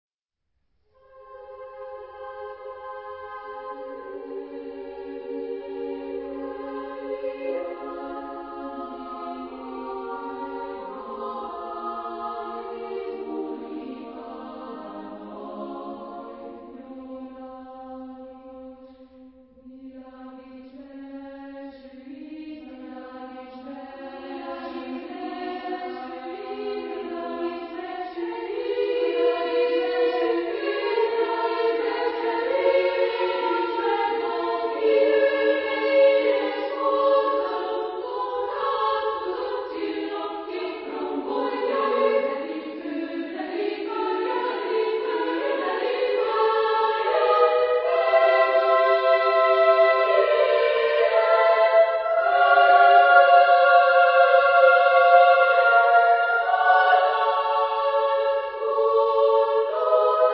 Genre-Stil-Form: zeitgenössisch ; Chor ; Suite
Chorgattung: SMA  (3 Frauenchor Stimmen )